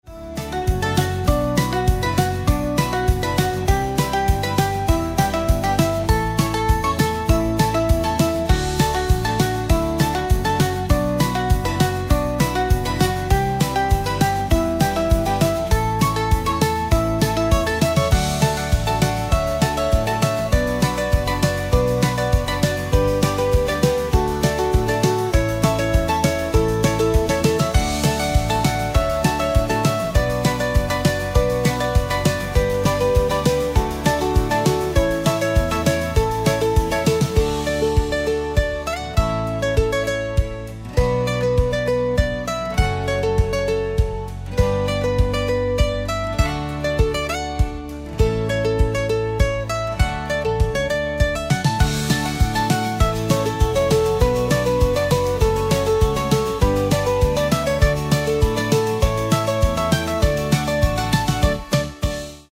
Fröhlicher Sound
Musik